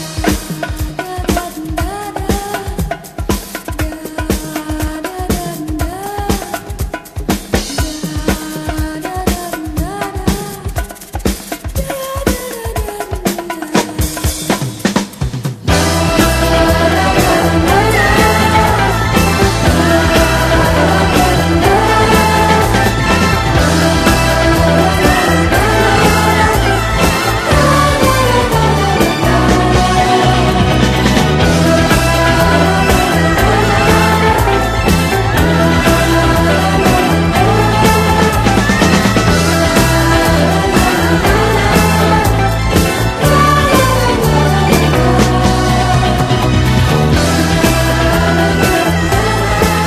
強力ジャズ・ファンク/レア・グルーヴを多数収録した人気コンピ・シリーズ！